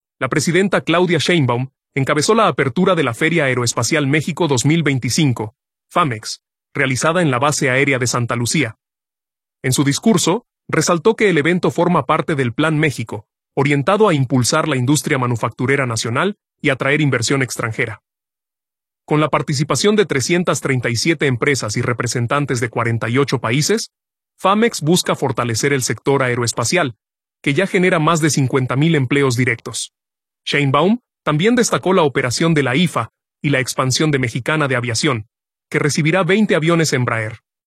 La presidenta Claudia Sheinbaum encabezó la apertura de la Feria Aeroespacial México 2025 (FAMEX), realizada en la Base Aérea de Santa Lucía. En su discurso, resaltó que el evento forma parte del Plan México, orientado a impulsar la industria manufacturera nacional y atraer inversión extranjera.